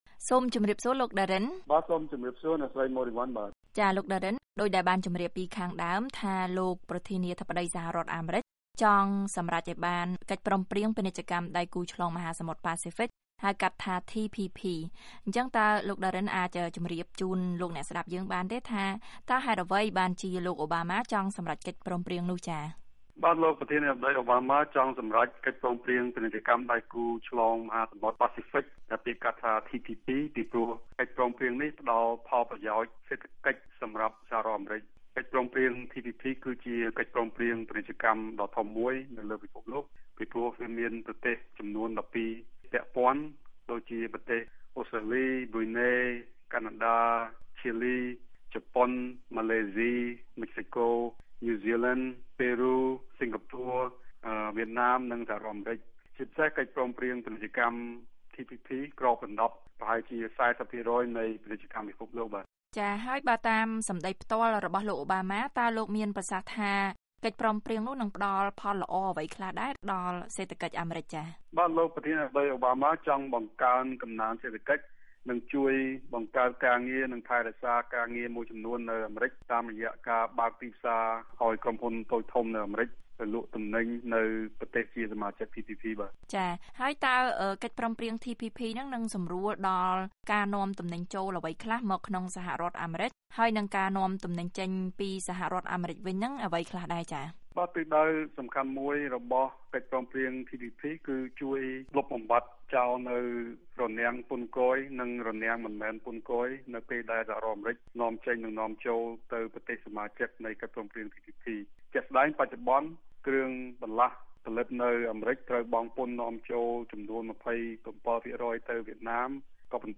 បទសម្ភាសន៍